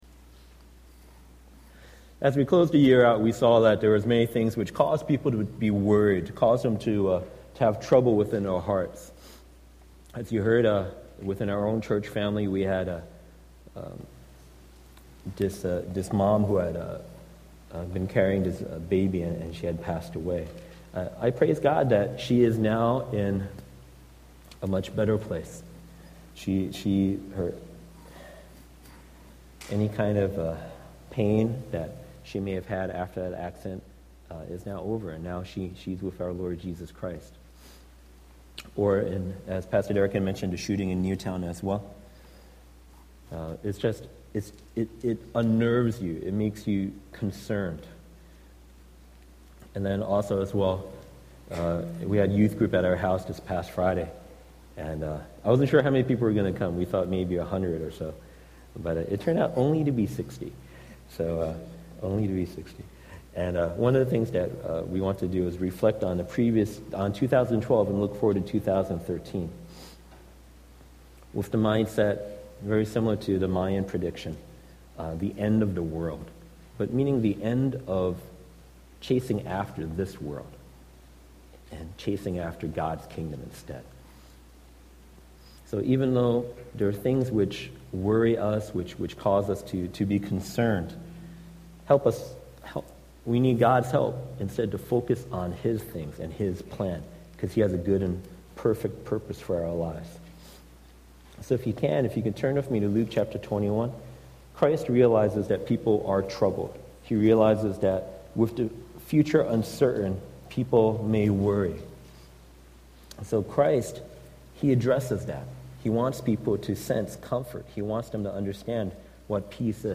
The sermon begins by acknowledging the worries and troubles faced by the congregation, including personal losses and tragic events like the Newtown shooting.